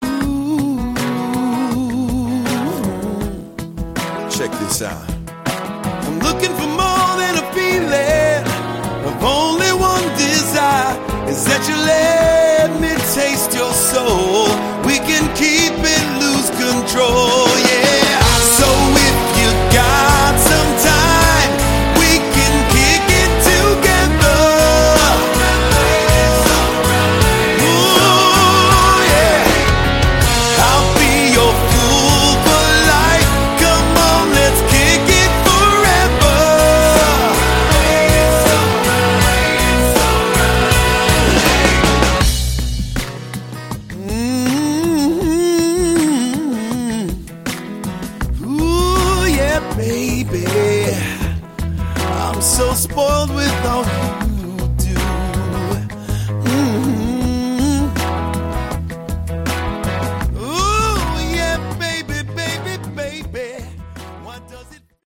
Category: Rock / Funk
vocals
guitar
drums
bass